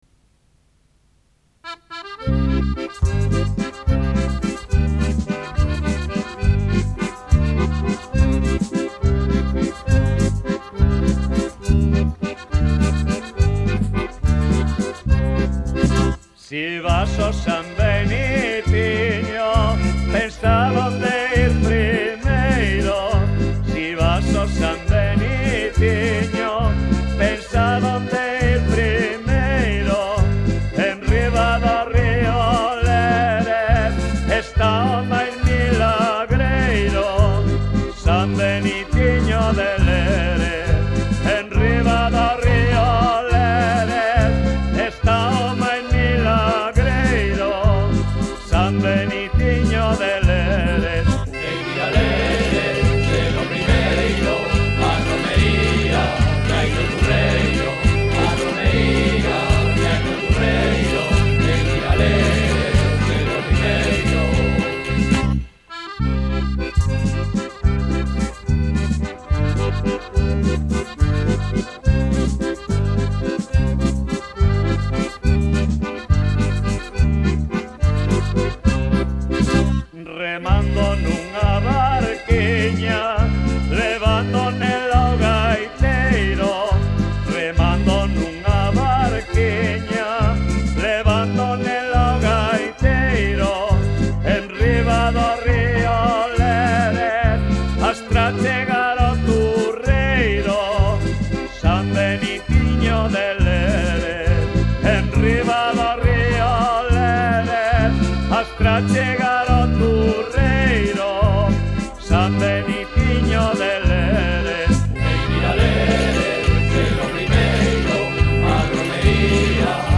Música:Popular